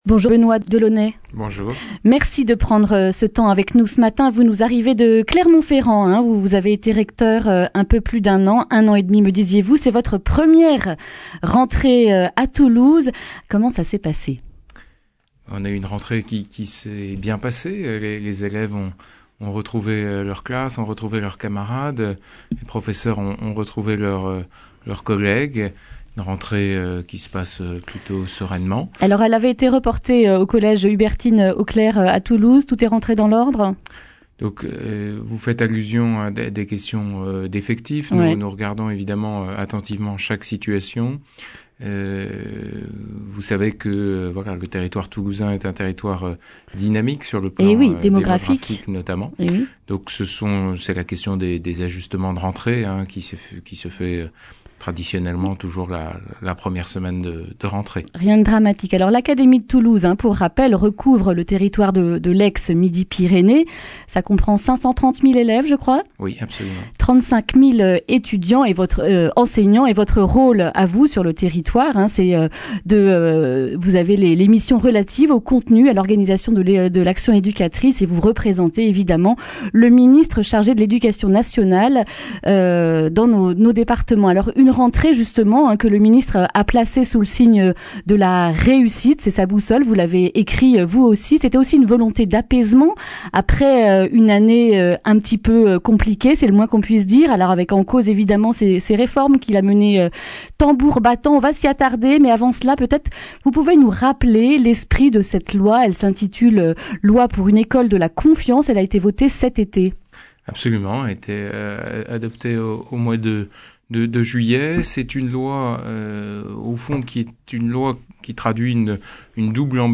Benoit Delaunay, nouveau recteur de l’Académie de Toulouse, est l’invité de Présence Matin